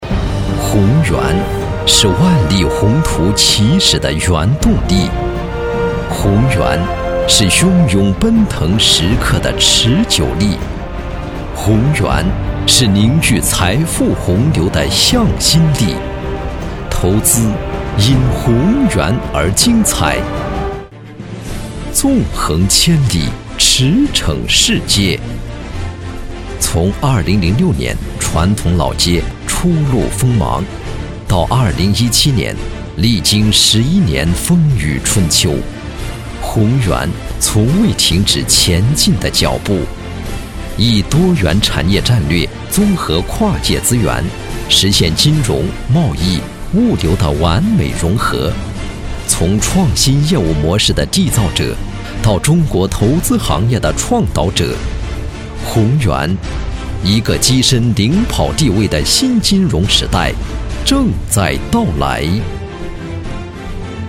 • 男S390 国语 男声 宣传片-鸿源-企业专题-大气浑厚 大气浑厚磁性|沉稳|娓娓道来